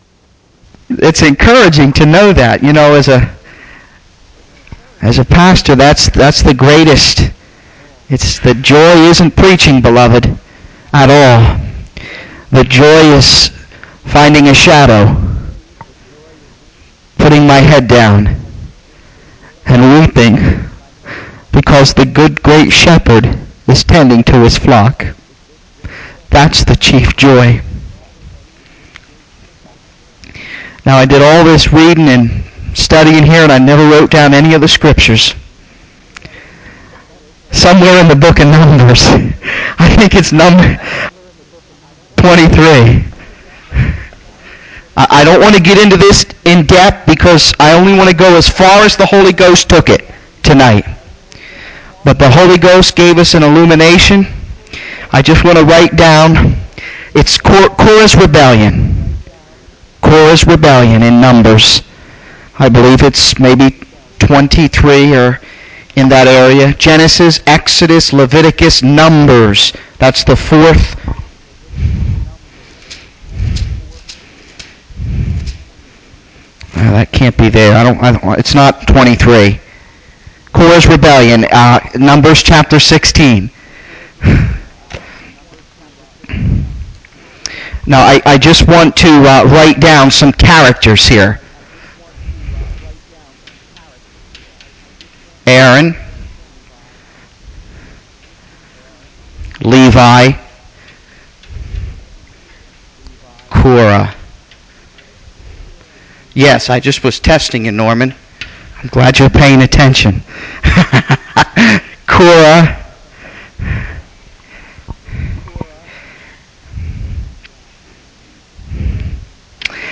The sermon draws parallels between this Old Testament account and the New Testament understanding of spiritual priesthood, urging believers to recognize their unique callings and the necessity of the Holy Spirit's guidance.